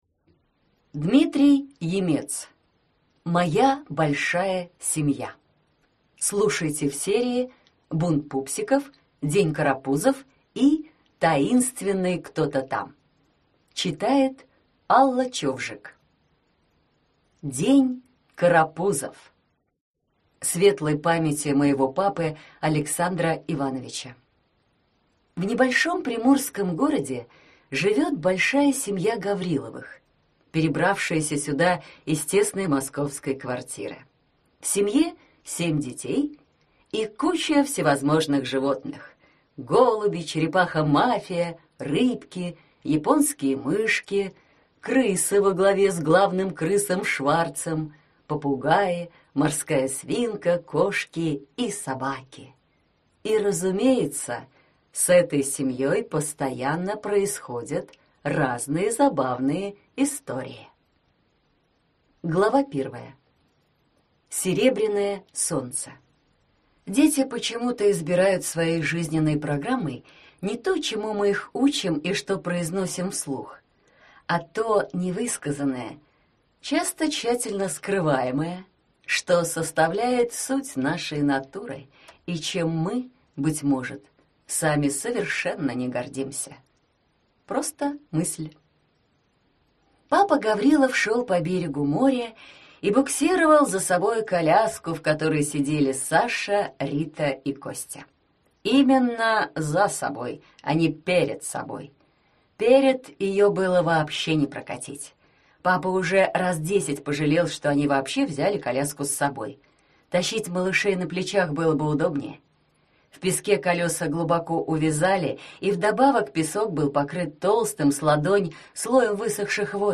Аудиокнига День карапузов | Библиотека аудиокниг